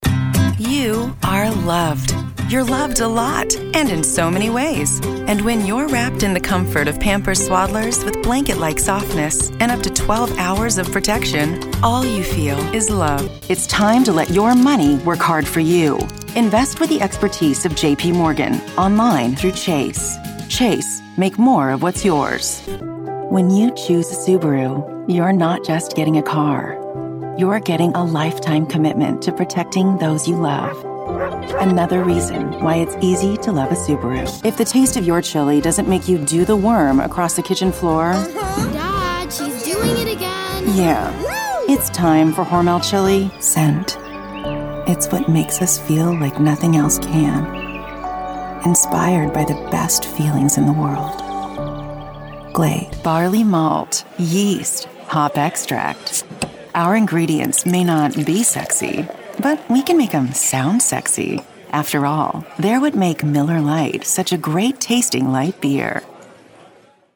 Location: Los Angeles, CA, USA Languages: english Accents: standard us | natural Voice Filters: VOICEOVER GENRE COMMERCIAL 💸 COMMERCIAL FILTER warm/friendly